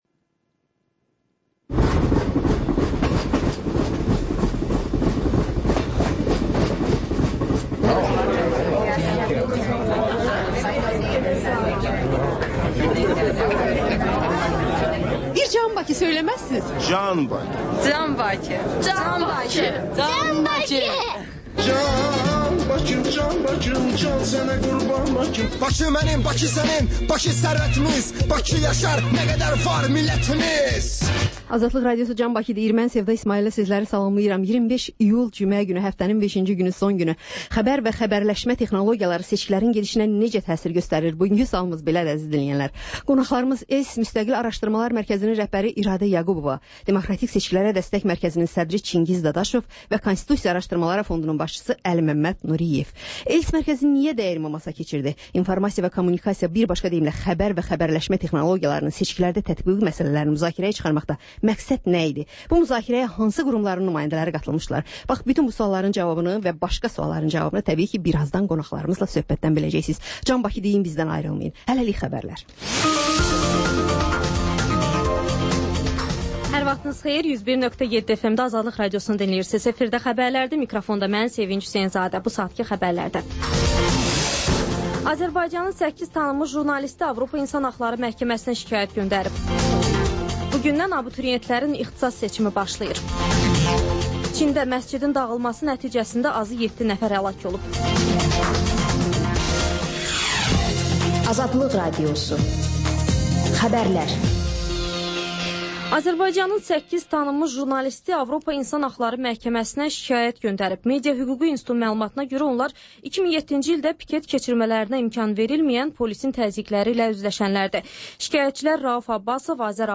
Xəbərlər, sonra CAN BAKI verilişi: Bakının ictimai və mədəni yaşamı, düşüncə və əyləncə həyatı…(Təkrarı saat 14:00-da)